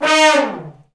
Index of /90_sSampleCDs/Roland LCDP06 Brass Sections/BRS_Section FX/BRS_Fat Falls